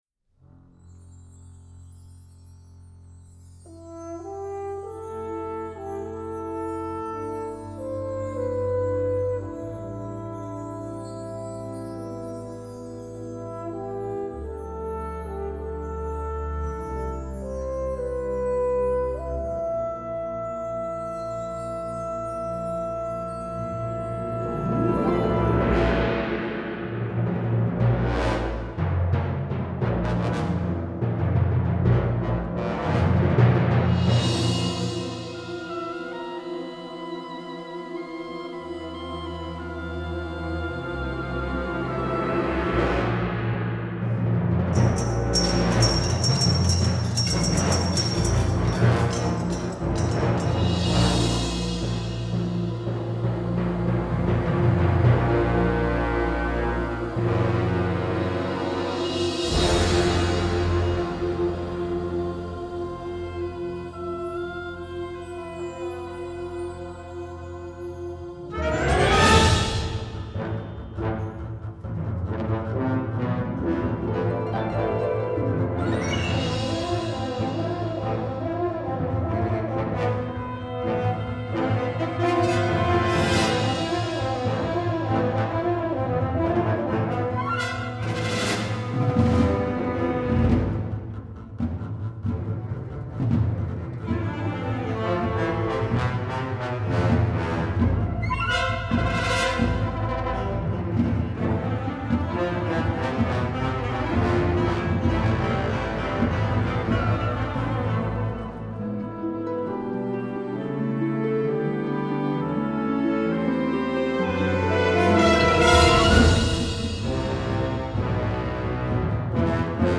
6:43 Minuten Besetzung: Blasorchester PDF